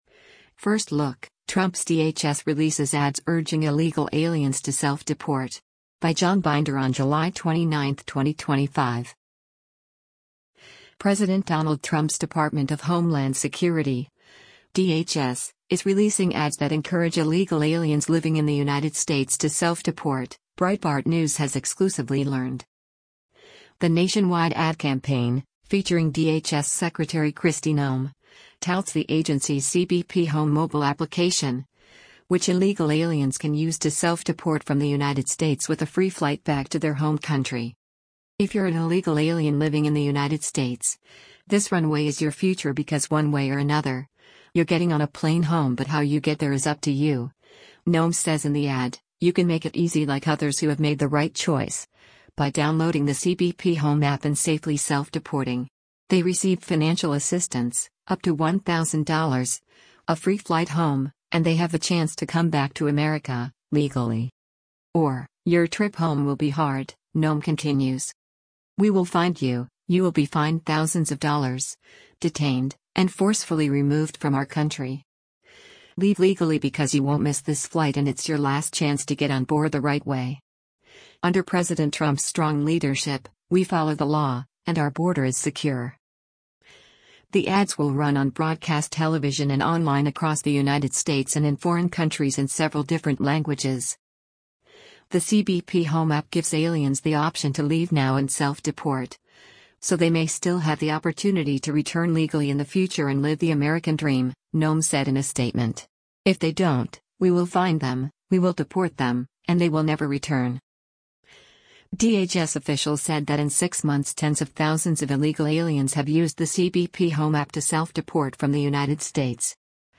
The nationwide ad campaign, featuring DHS Secretary Kristi Noem, touts the agency’s CBP Home mobile application, which illegal aliens can use to self-deport from the United States with a free flight back to their home country.